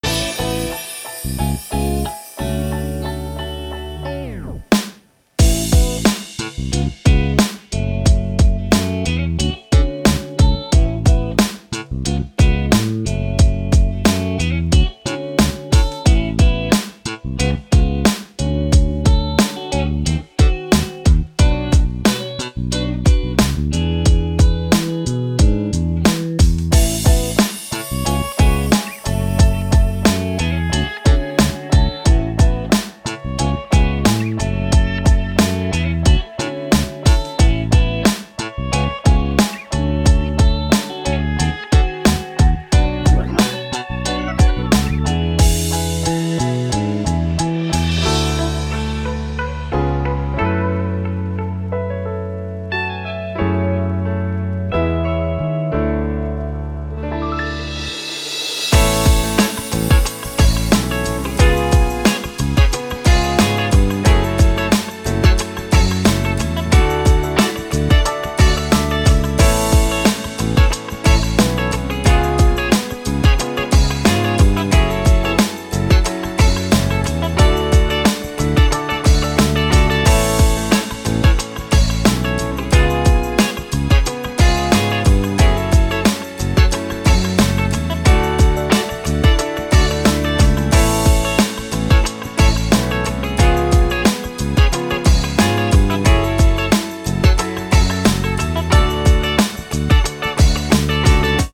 Instrumental Ver.